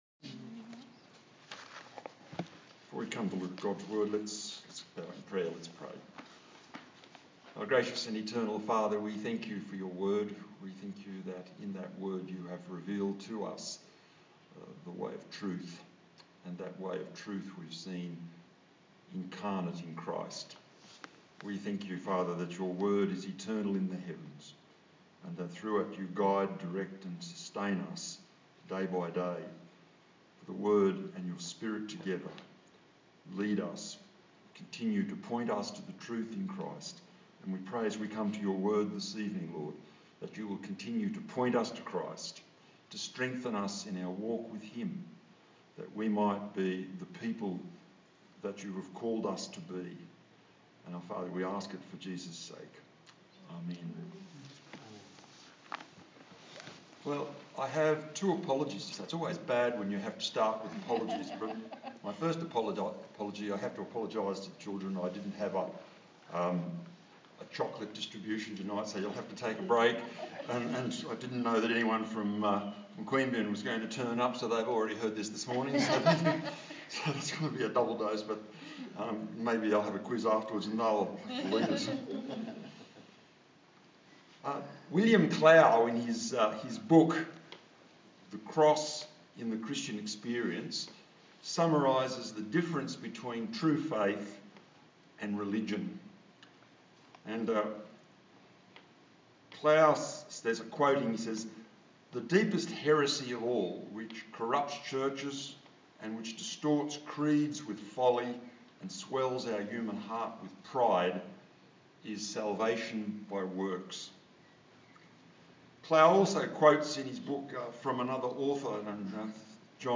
Galatians Passage: Galatians 2:1-21 Service Type: TPC@5